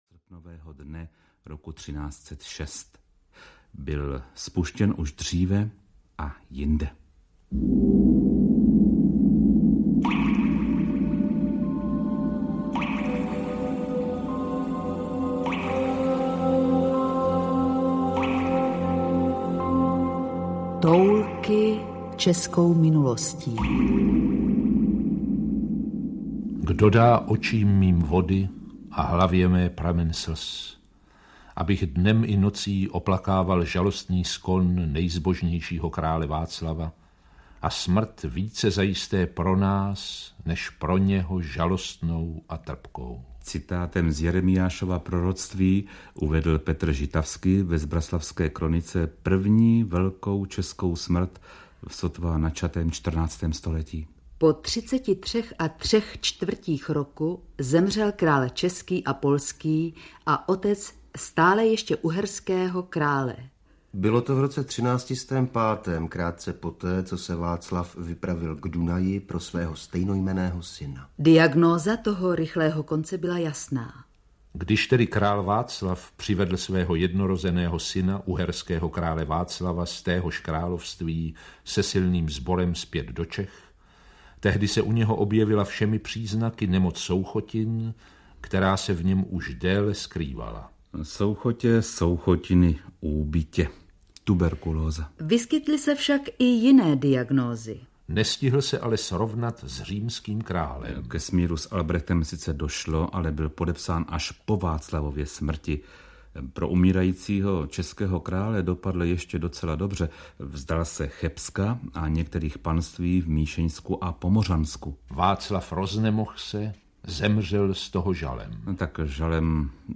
Toulky českou minulostí 101 – 150 audiokniha
Ukázka z knihy